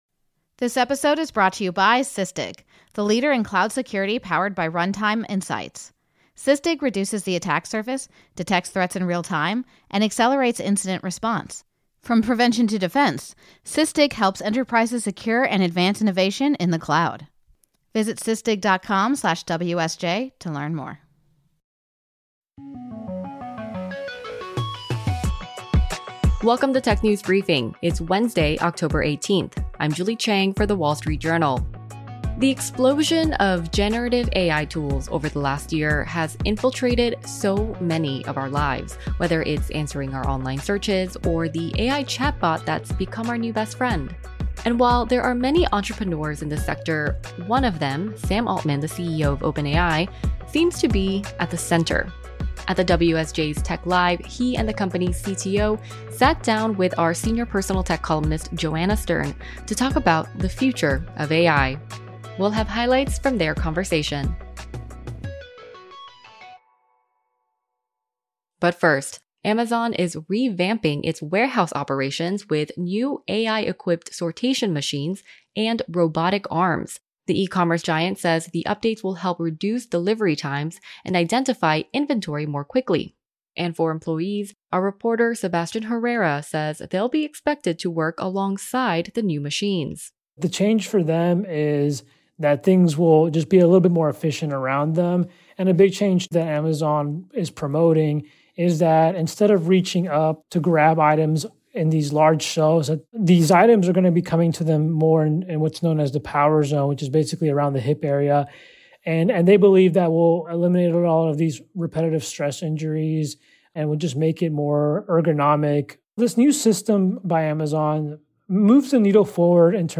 The company at the center of the generative artificial intelligence revolution took the stage at the WSJ’s Tech Live event. OpenAI’s CEO Sam Altman and CTO Mira Murati sat down with WSJ senior personal tech columnist Joanna Stern to talk about AGI, the capabilities of future GPT models and more.